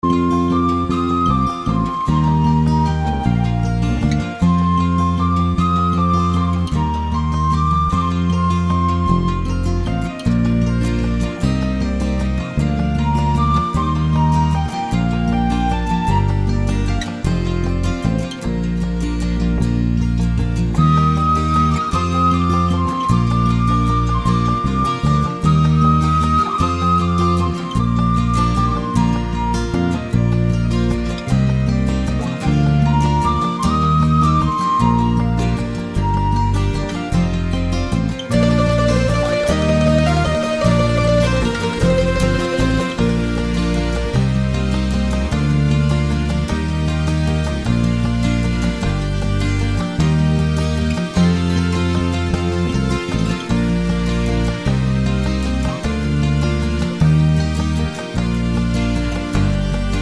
Irish Backing Tracks for St Patrick's Day